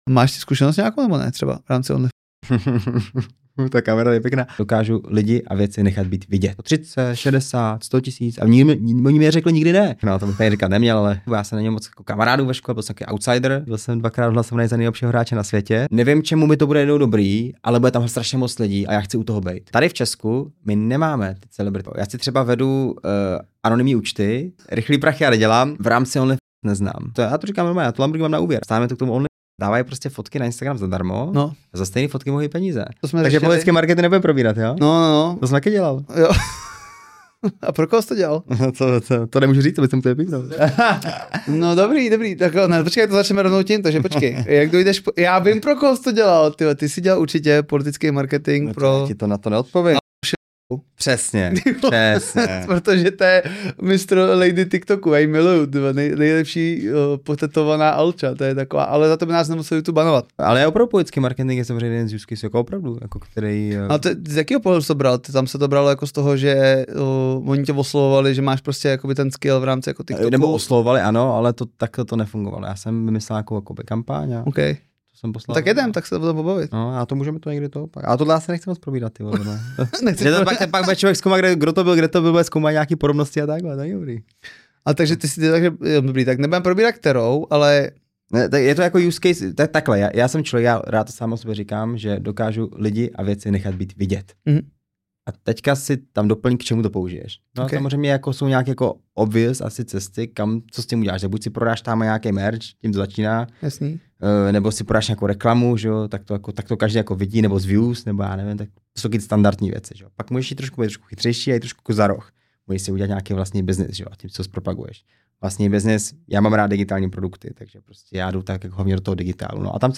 - Nečekaně klidný, lidský, ale i drsně upřímný rozhovor.